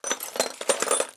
SFX_Metal Sounds_11.wav